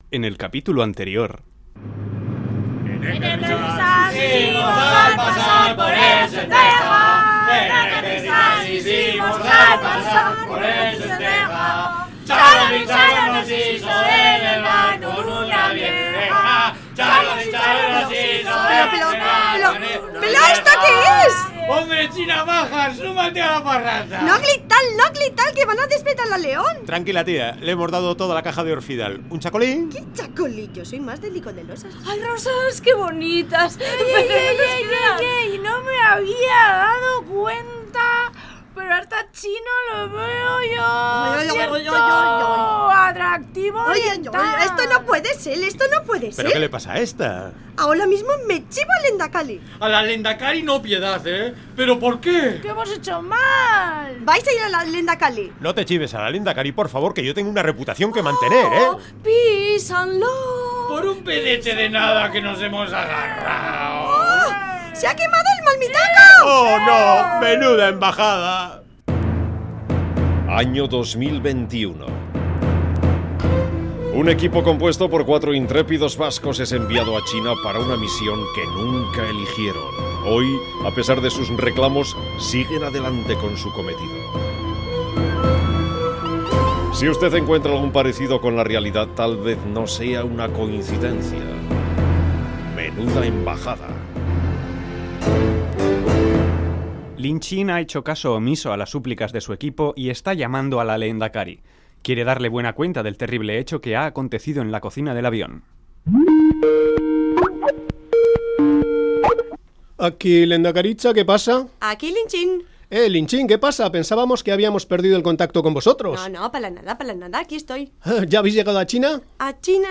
MENUDAEMBAJADA es una radionovela que tiene como protagonistas a una china y 4 vascos con una misión secreta: abrir la primera embajada de Euskadi en China.